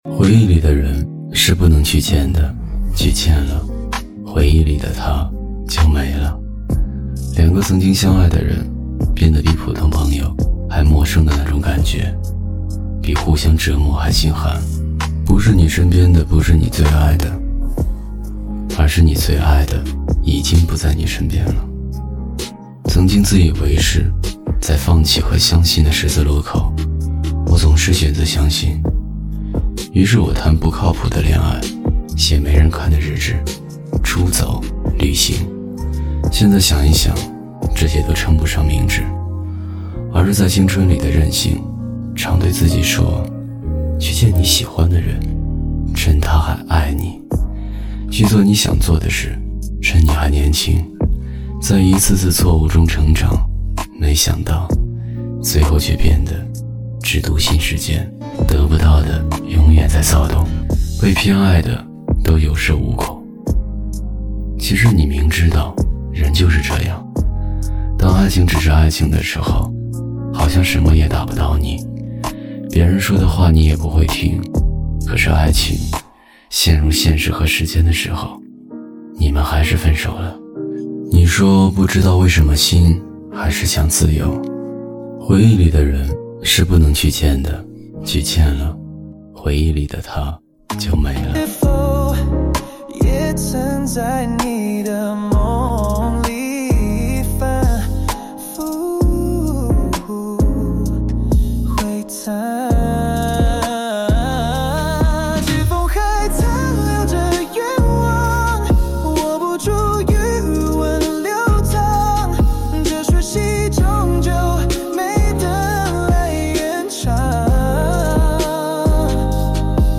擅長中文、日文、台語、韓語多語創作，曲風融合 EDM、K-POP 與抒情電子。